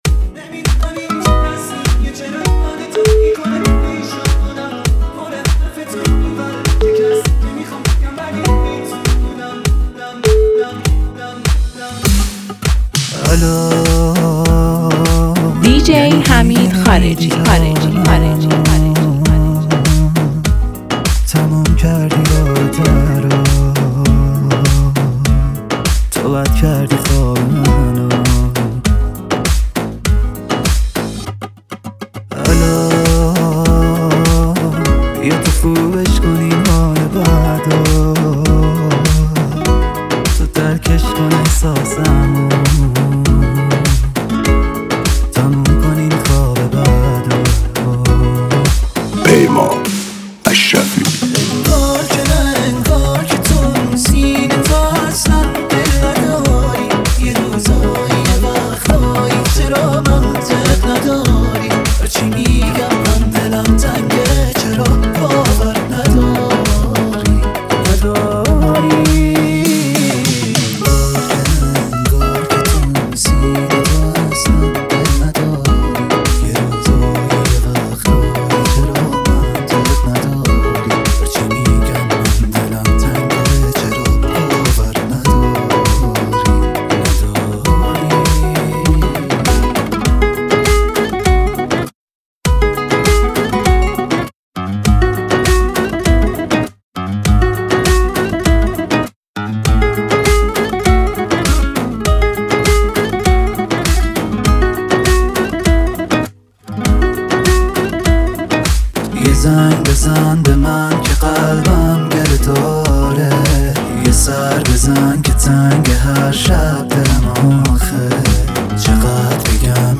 ریمیکس بیس دار